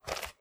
STEPS Dirt, Walk 20.wav